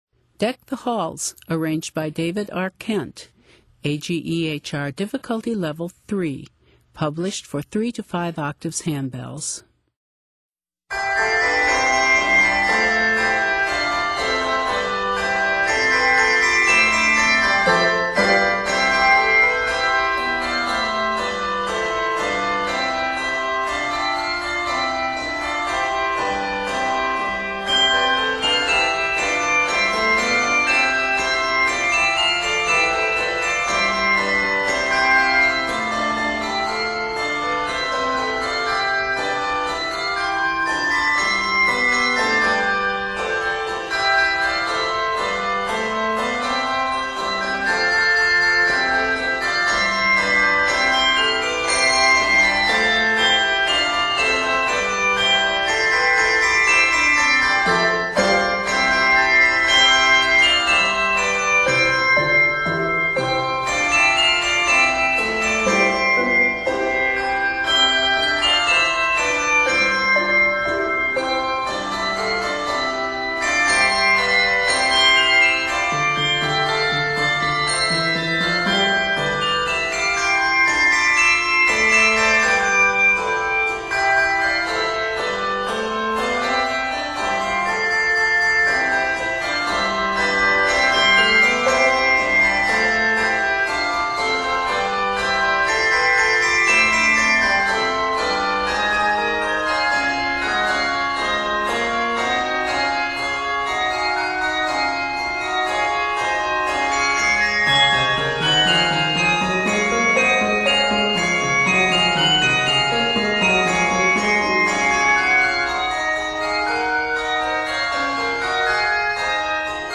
Arranged in C and Db Major, measures total 63.